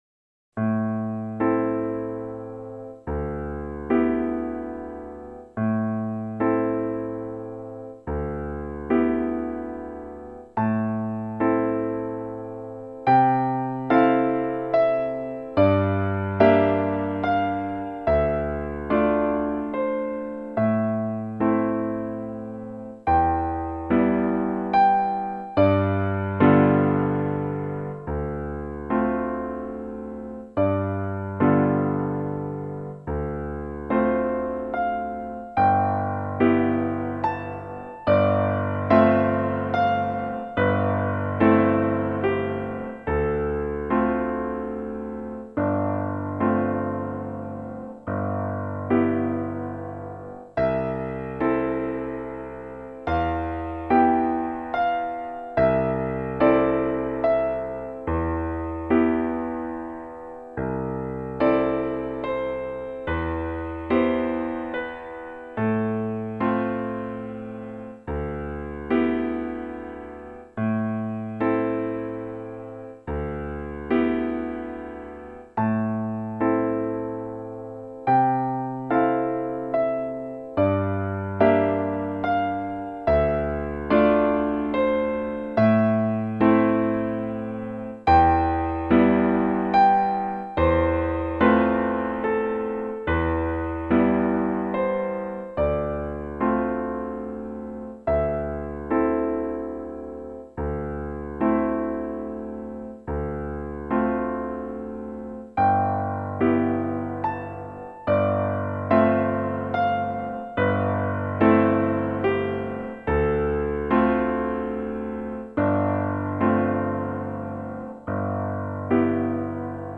シンセサイザーによるＭＩＤＩの演奏の録音です。
◆ピアノ曲